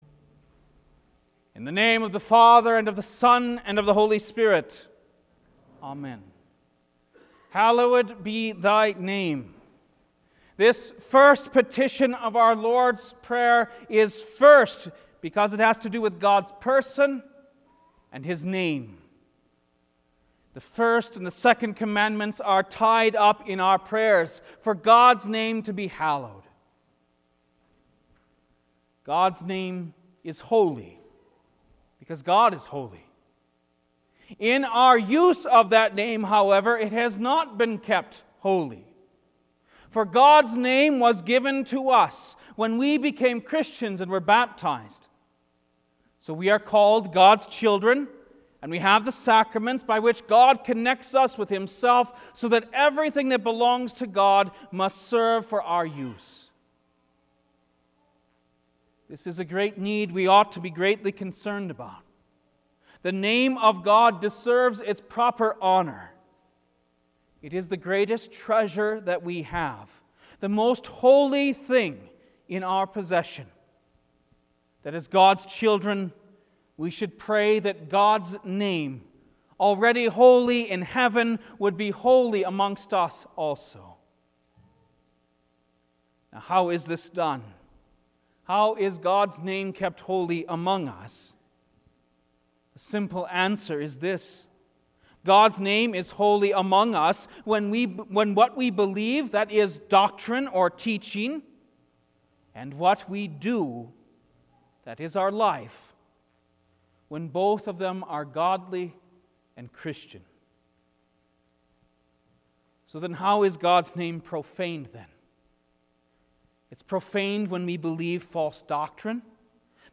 Lenten Midweek Service One